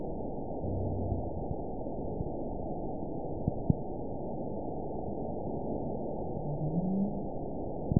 event 922524 date 01/25/25 time 19:46:53 GMT (10 months, 1 week ago) score 9.47 location TSS-AB04 detected by nrw target species NRW annotations +NRW Spectrogram: Frequency (kHz) vs. Time (s) audio not available .wav